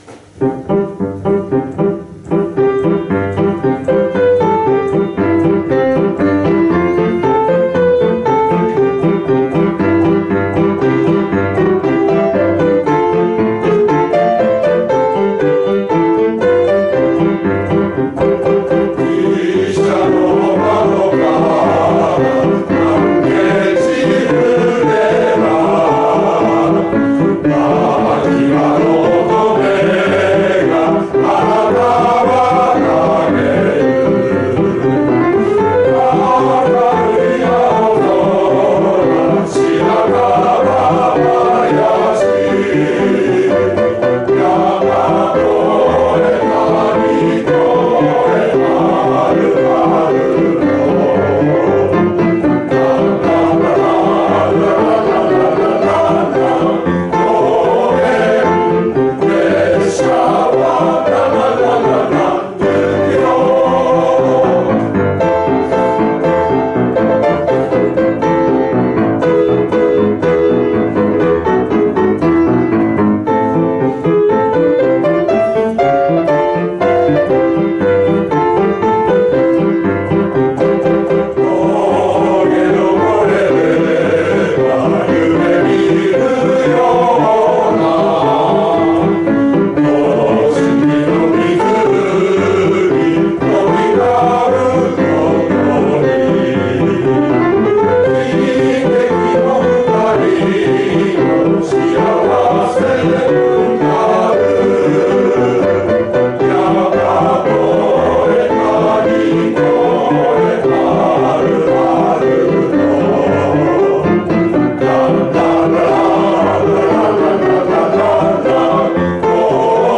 最後に「少年時代」 「高原列車は行く」を、通して歌いました。